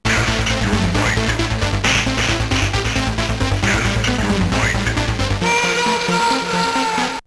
Ten sam oryginalny fragment utworu został zpróbkowany z komputera PC samplerem produkcji BIW.
Następnie odtworzone fragmenty z pamięci RAM commodore zostały zsamplowane przez kartę dźwiękową komputera PC i zapisane w formacie 22kHz 16 bitów mono.
4 bit sid $70]
mortal-4bit-sid-70.wav